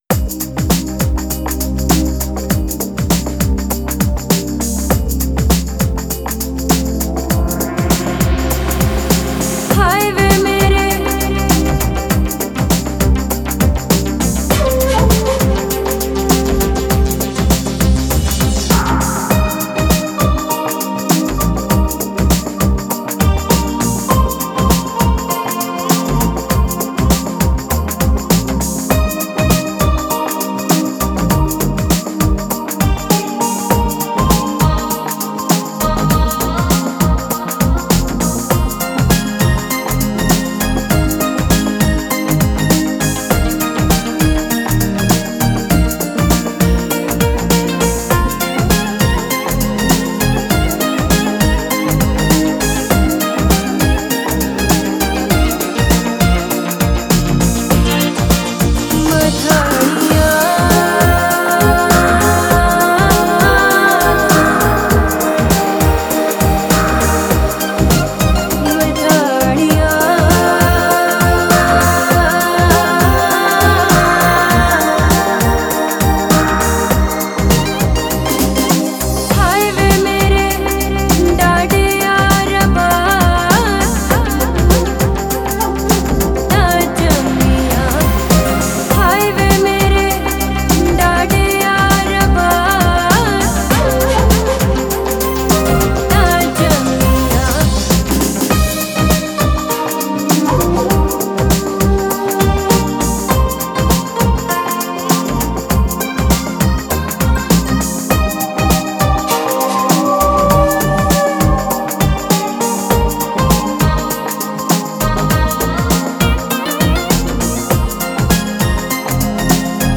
Genre: New Age, Enigmatic